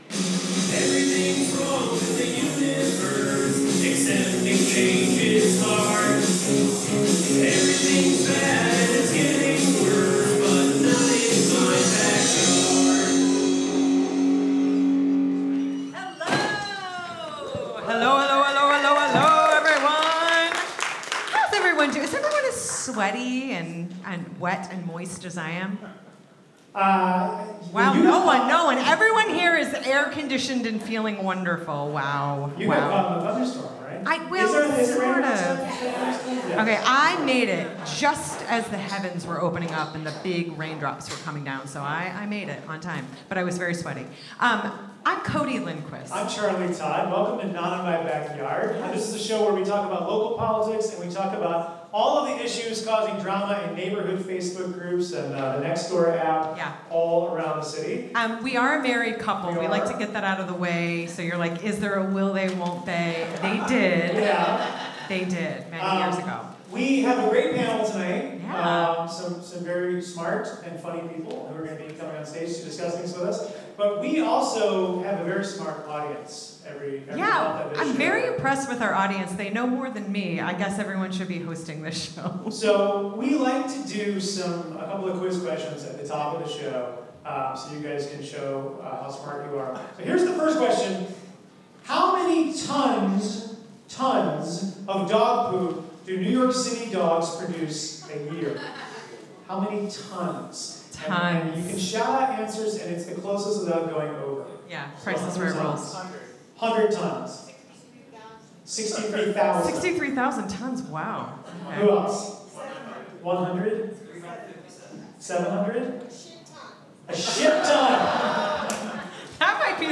Live form the UCB Theatre NY, we discuss parking space pools, weed stores and schools, and the latest in the race for NYC mayor.